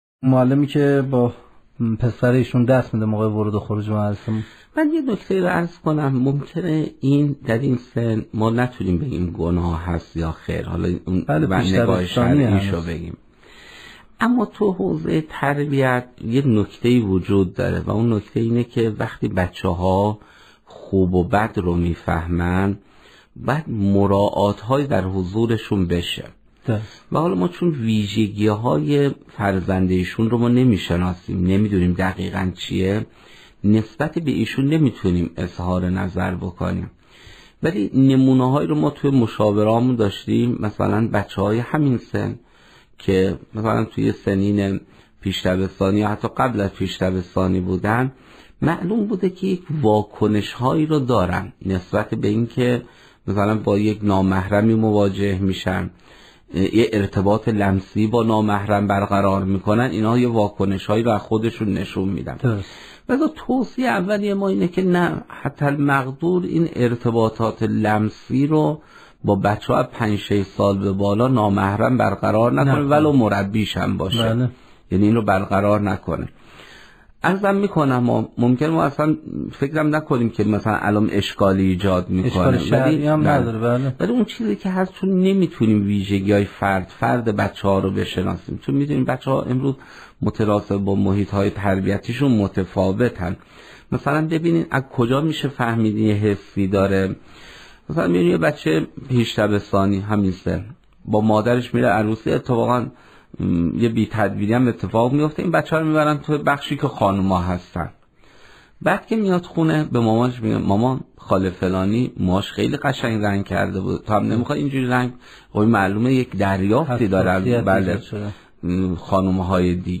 سخنرانی صوتی